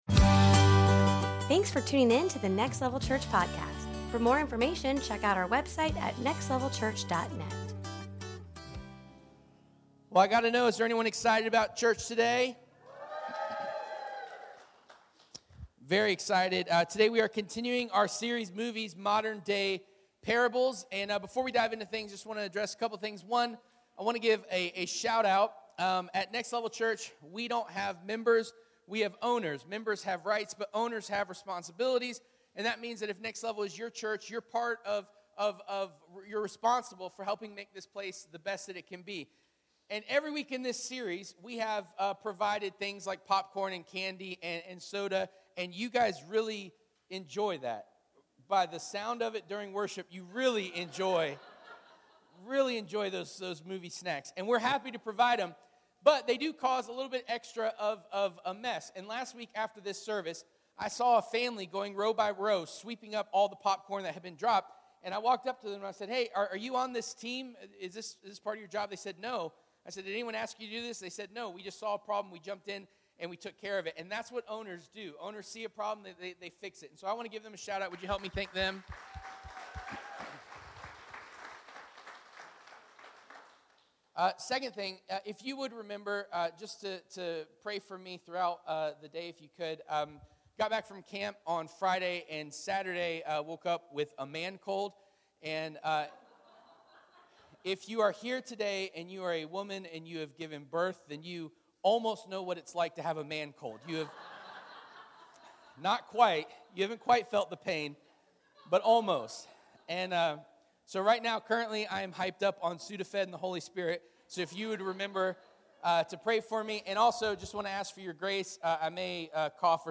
Service Type: Sunday Morning
7-16-23-Sermon-.mp3